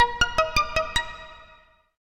Pizzicato.ogg